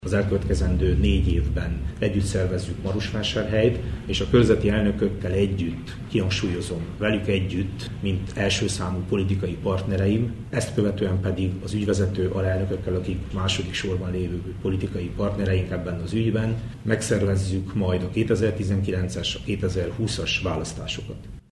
A 95-ös egészségügyi törvény módosításának – a napokban történt – képviselőházi megszavazásakor az RMDSZ tartózkodott, annak ellenére, hogy a módosítások kidolgozásában részt vett a szövetség – jelentette mai marosvásárhelyi sajtótájékoztatóján Vass Levente, a Képviselőház egészségügyi szakbizottságának titkára.